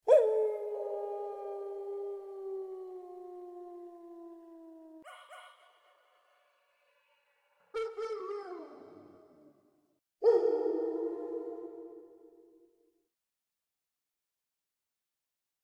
Wolf Heulen
Das Heulen eines Wolfes ist ein tiefes, langgezogenes Geräusch, das über große Entfernungen hinweg gehört werden kann, oft über mehrere Kilometer.
Das-Wolf-Heulen-u_ygwlkmquqg.mp3